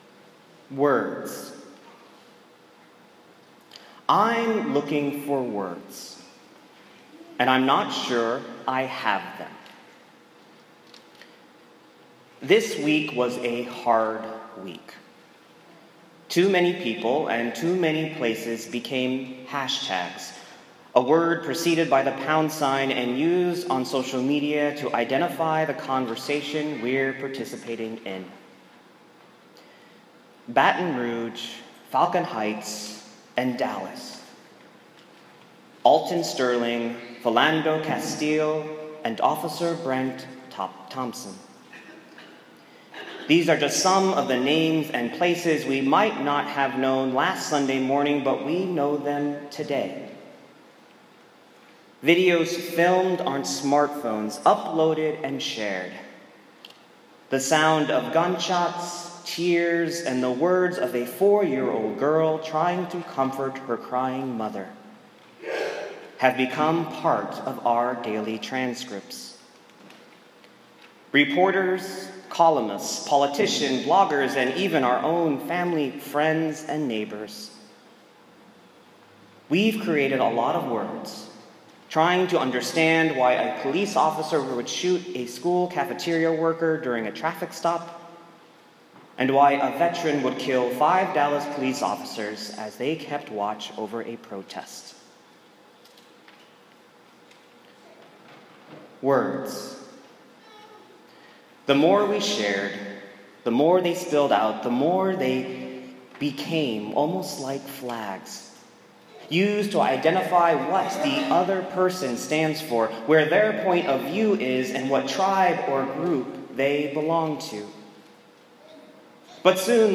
Do: a sermon on the Good Samaritan after Baton Rouge, Falcon Heights, and Dallas.